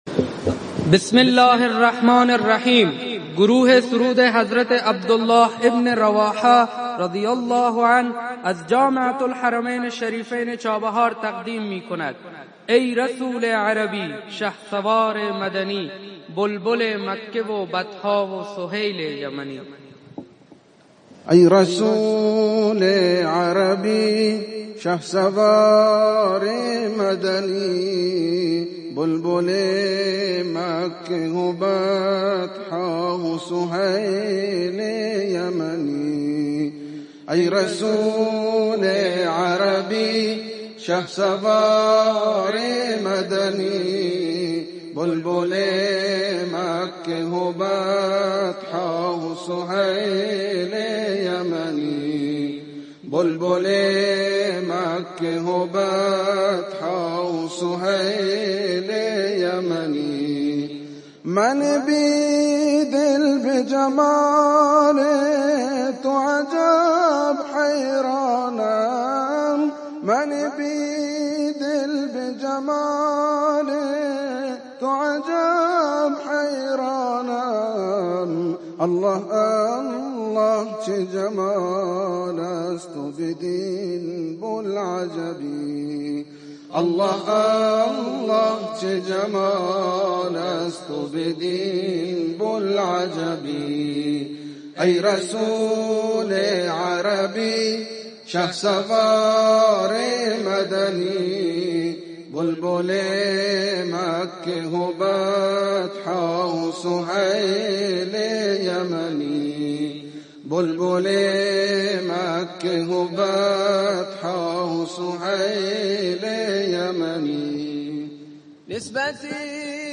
فایل های صوتی همایش دانش آموختگی طلاب جامعةالحرمین چابهار 1401
5 اجرای گروه سرود عبدالله بن رواحه
05 گروه سرود عبدالله بن رواحه.mp3